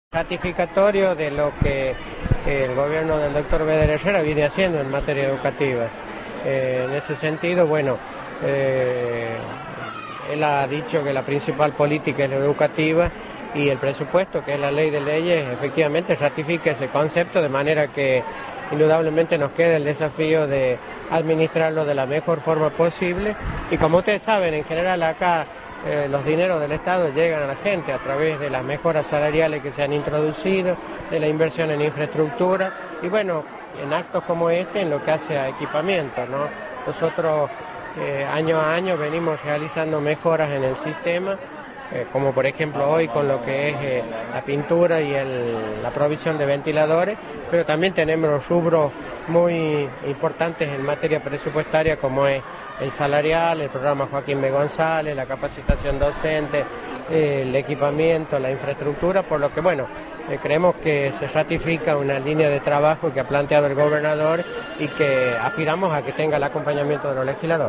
Claudia Ortiz, secretaria de Hacienda municipal, por Radio Rioja
Ortiz habló por Radio Rioja, previo a la conferencia de prensa que brindará su cuerpo de abogado el lunes venidero en el Palacio Municipal.